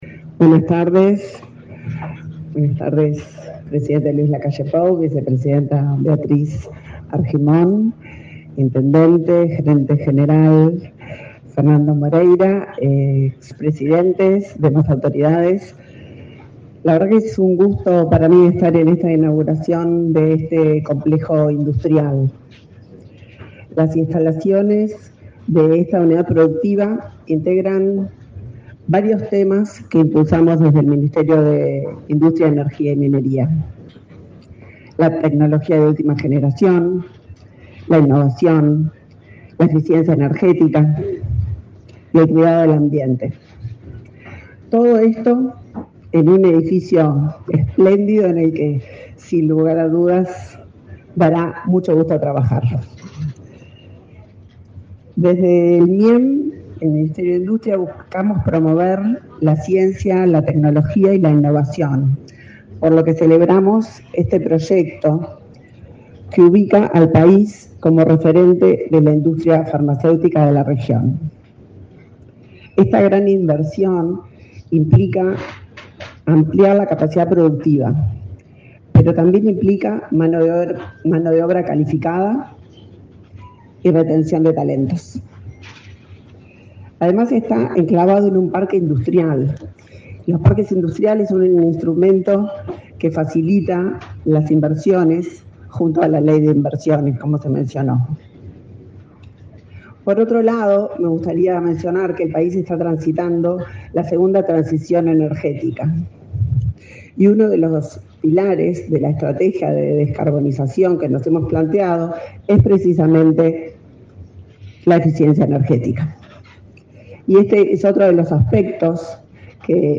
Palabras de la ministra de Industria, Elisa Facio
Palabras de la ministra de Industria, Elisa Facio 18/04/2024 Compartir Facebook X Copiar enlace WhatsApp LinkedIn Con la participación del presidente de la República, Luis Lacalle Pou, se realizó, este 18 de abril, la inauguración de la planta de Urufarma. En el evento disertó la ministra de Industria, Elisa Facio.